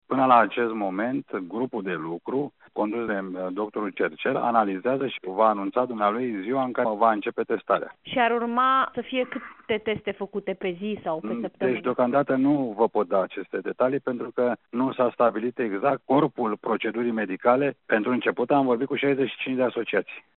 Viceprimarul Capitalei, Aurelian Bădulescu, spune că primarul Bucureștiului și medicul Streinu Cercel nu au renunțat la idee.